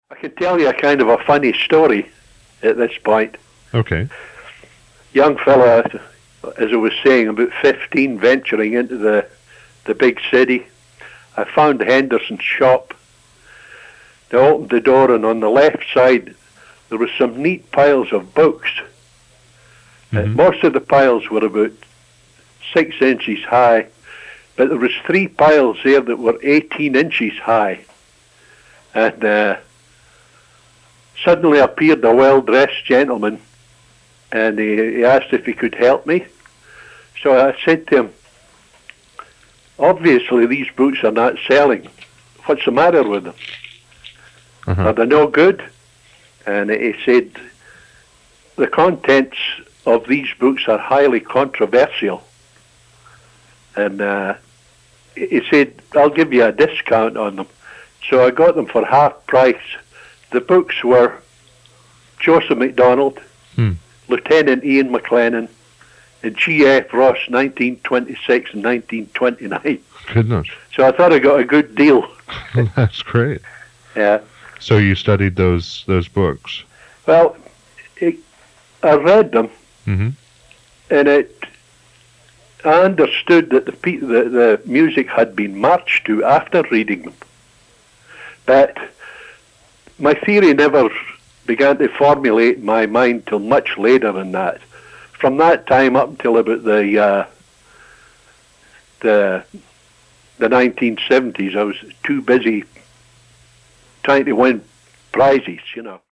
Here’s a digitized audio clip from the May 2002 pipes|drums Interview